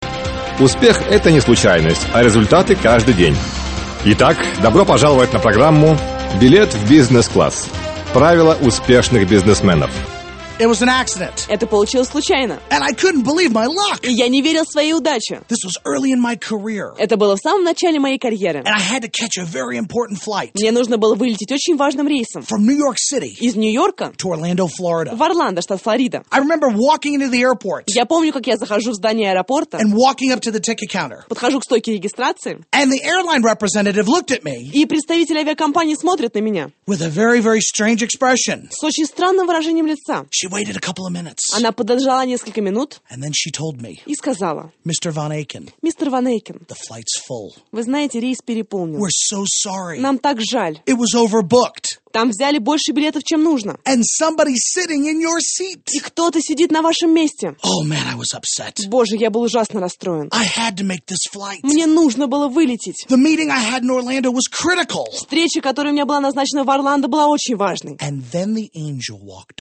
Аудиокнига Билет в бизнес-класс | Библиотека аудиокниг